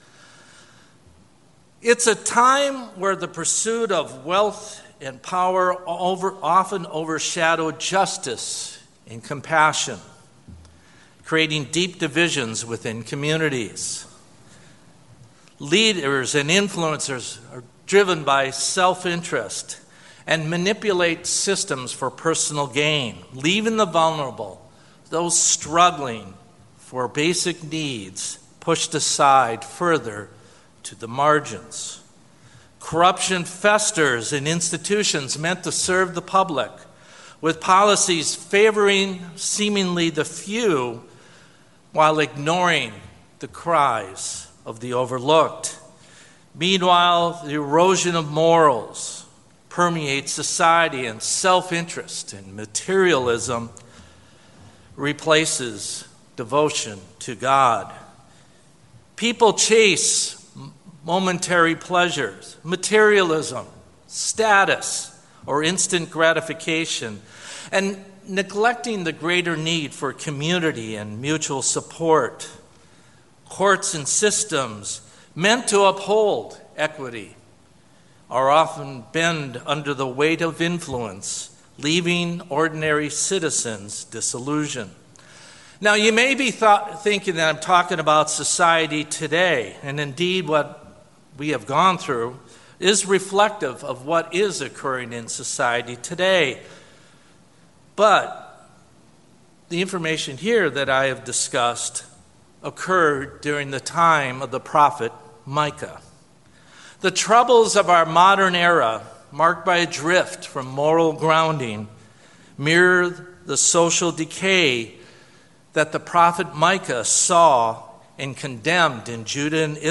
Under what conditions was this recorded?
Given in Los Angeles, CA Bakersfield, CA